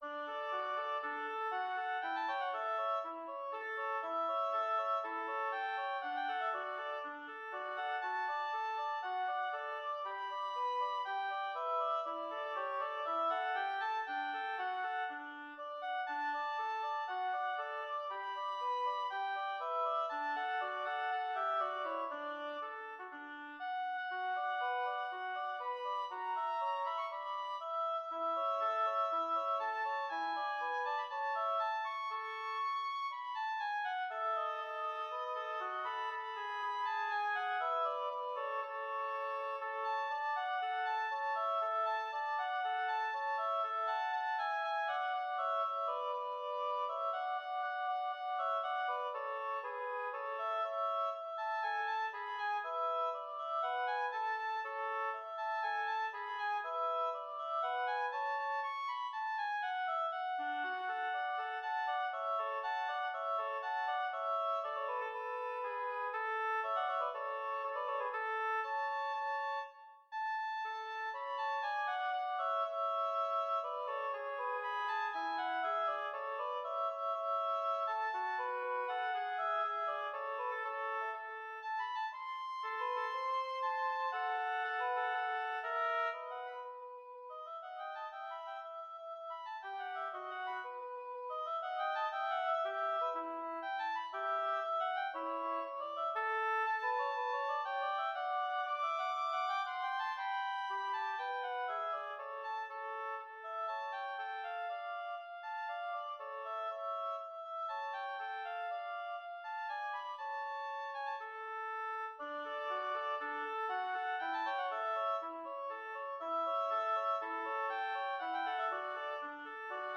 Intermediate oboe duet
oboe music